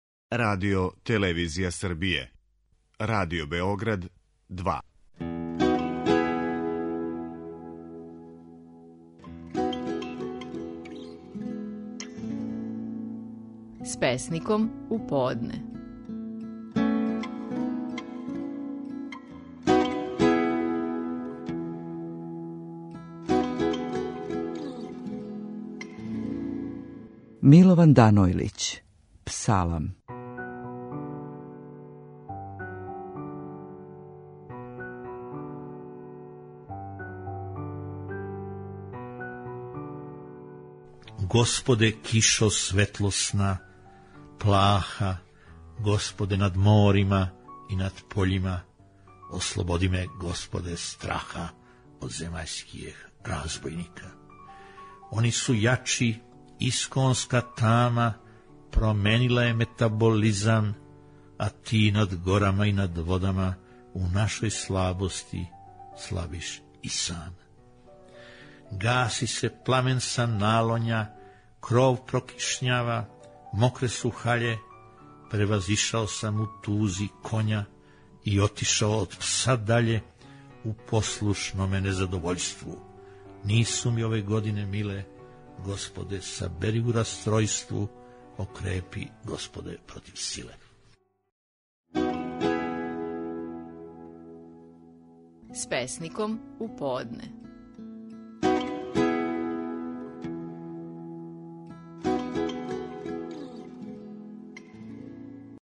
Стихови наших најпознатијих песника, у интерпретацији аутора.
Милован Данојлић говори своју песму: „Псалам".